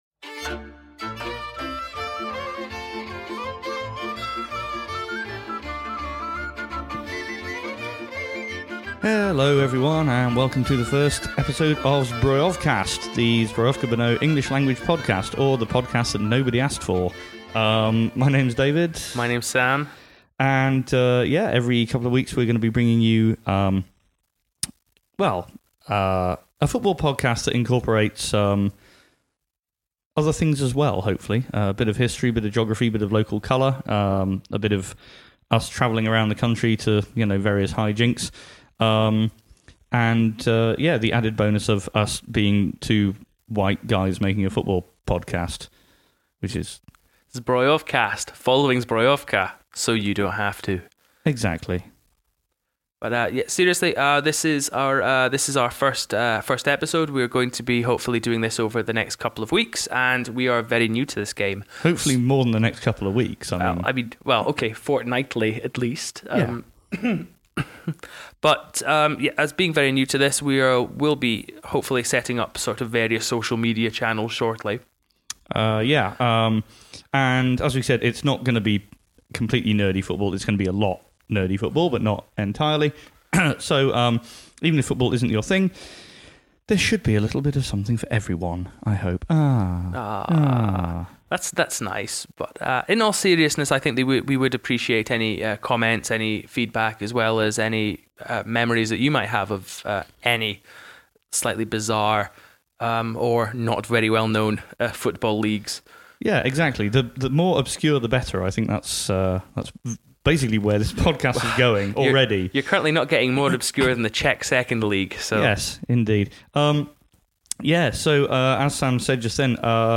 A 2018/19 preview episode. What and where is Brno, who the club is and how it's in this state, new signings and old faces, coughing, and a railway station jingle.